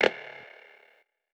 07_Clap_12_SP.wav